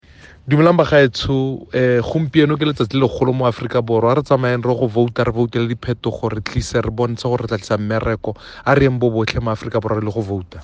The following remarks were delivered today by Democratic Alliance (DA) Leader, Mmusi Maimane, while casting his vote in Dobsonville, Soweto.